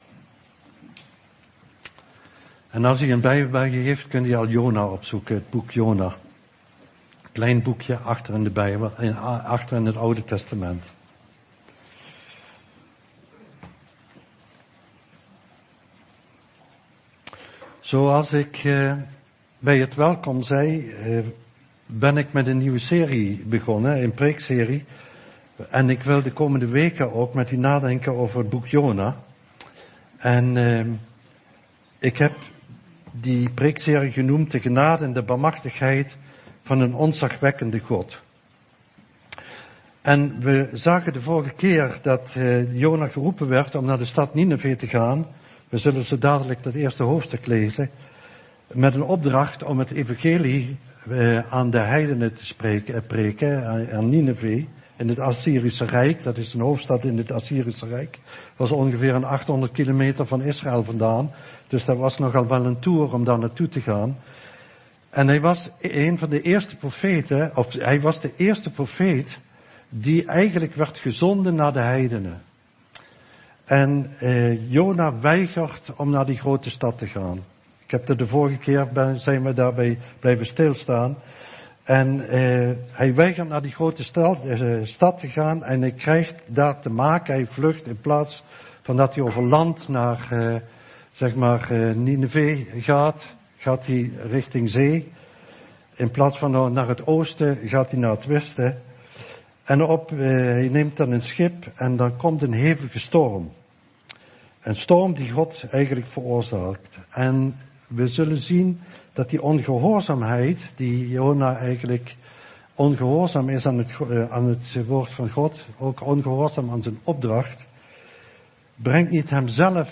Een preek over 'Jona - wie is mijn naaste?'.